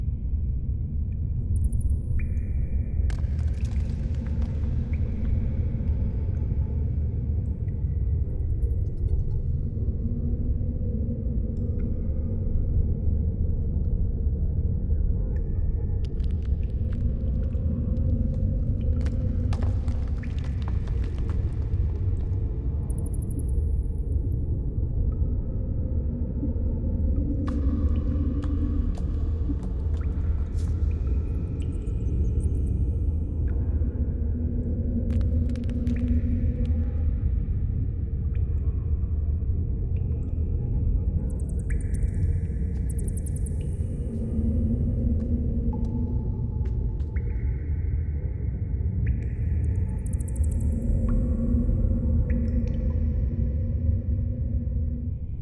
Ambience_Cave_00.wav